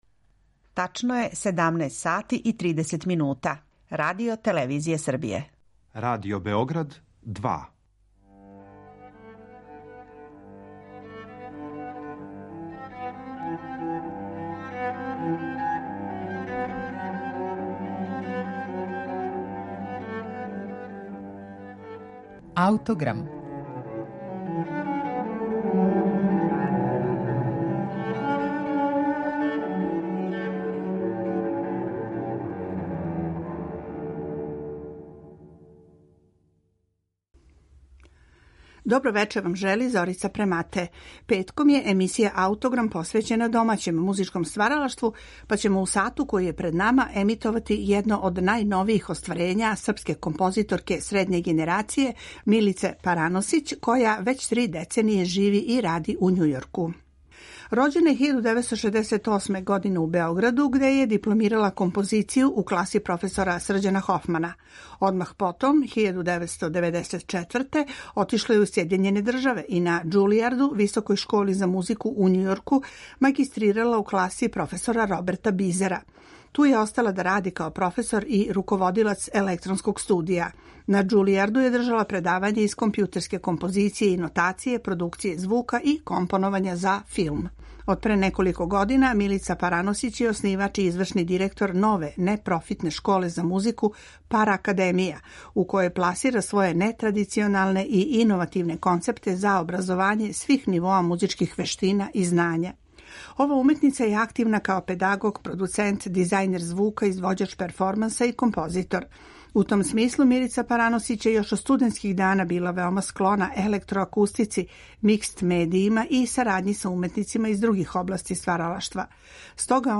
камерна опера
чланице вокално-инструменталног ансамбла